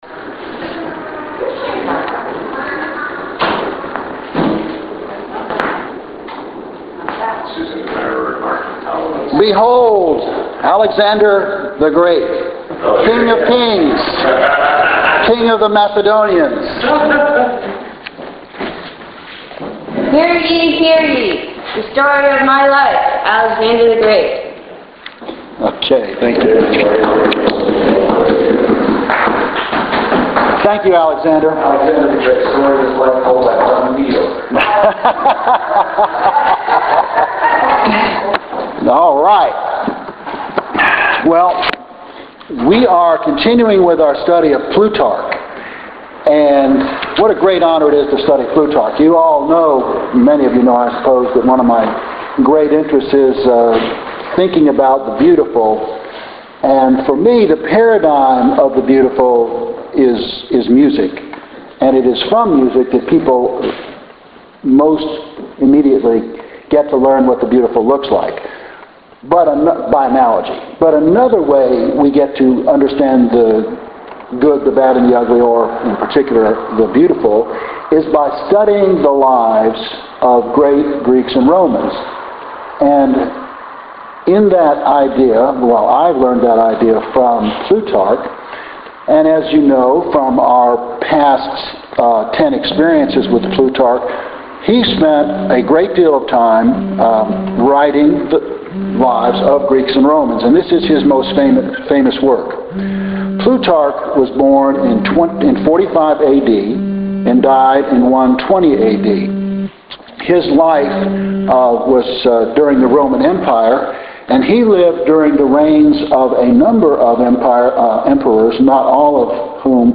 Alexander the Great: FOR Sunday School lecture from Plutarch’s Lives; Sunday October 6, 2013